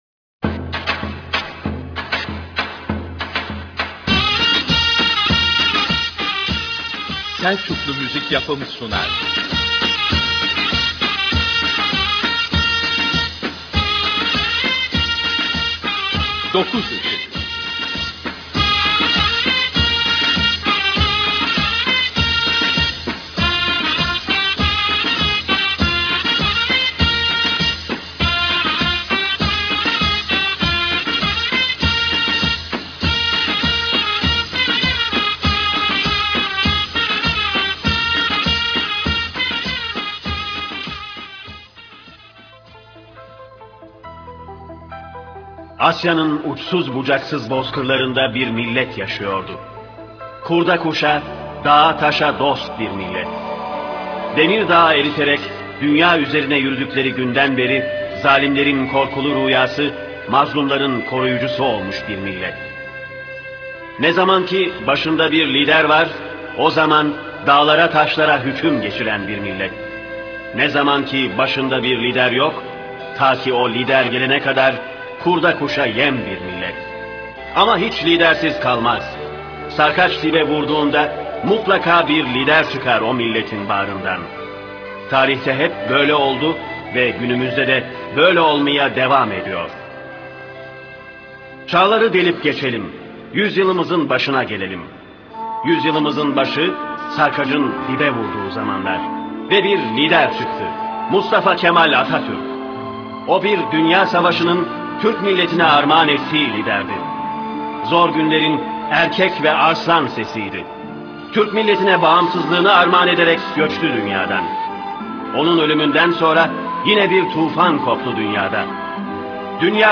Dokuz Işık Doktrini sesli olarak anlatılıyor.